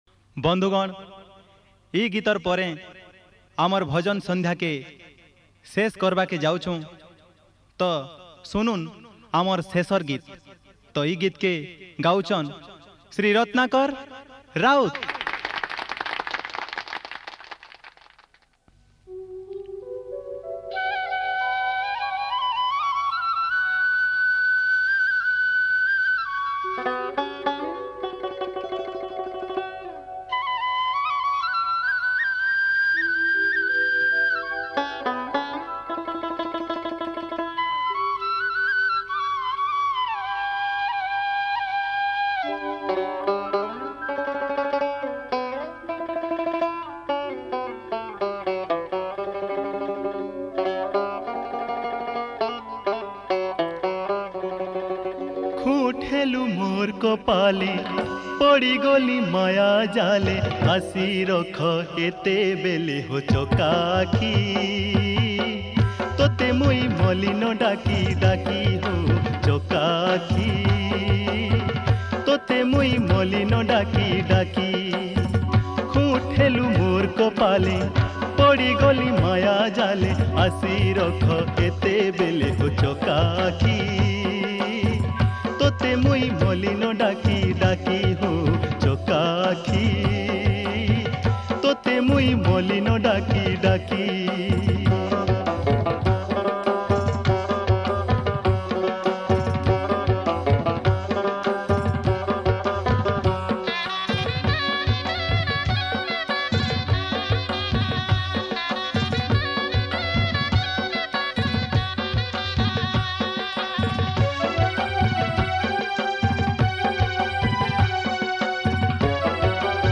Category: Sambalpuri Bhajan Sandhya